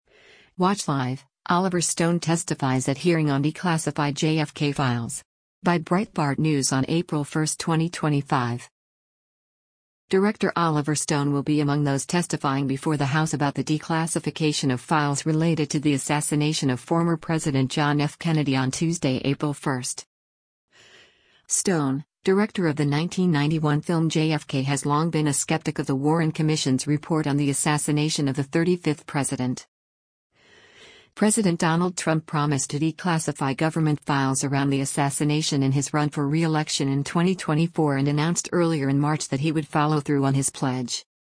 Director Oliver Stone will be among those testifying before the House about the declassification of files related to the assassination of former President John F. Kennedy on Tuesday, April 1.